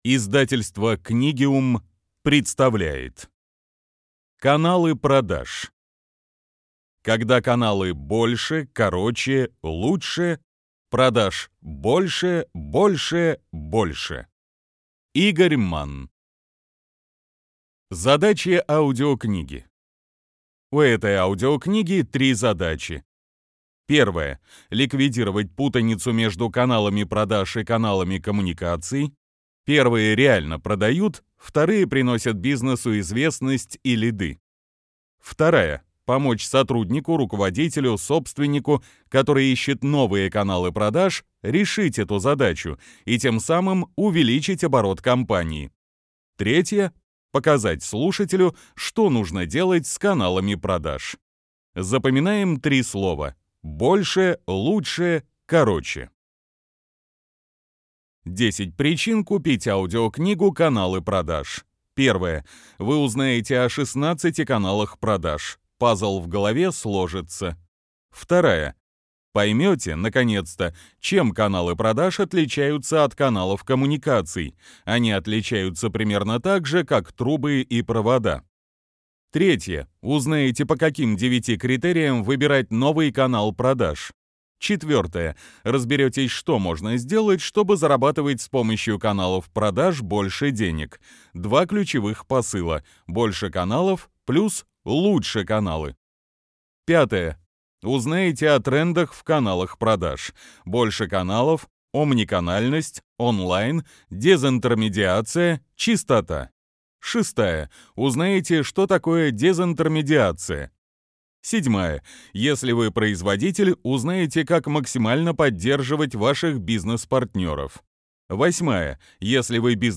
Аудиокнига Каналы продаж | Библиотека аудиокниг